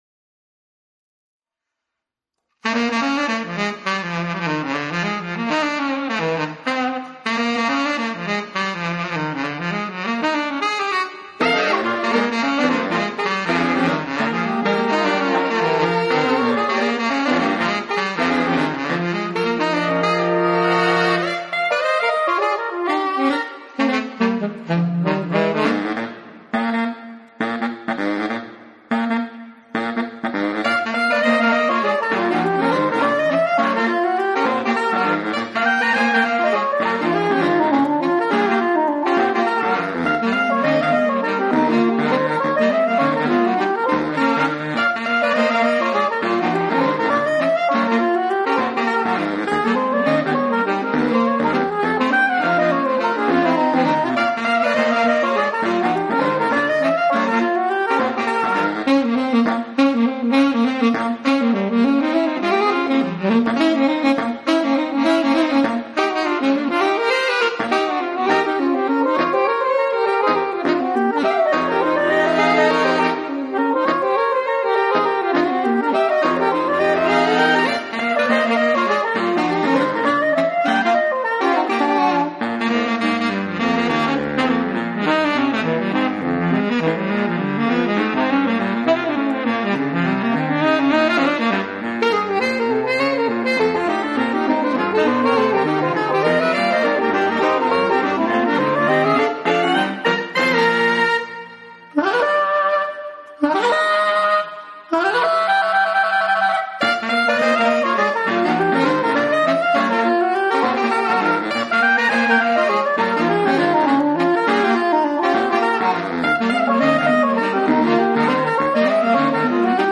Multi-track performance
playing all four saxophone parts!
A fabulous pseudo big band style piece
saxes